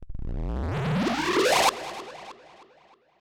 K-6 Distorto Zap.wav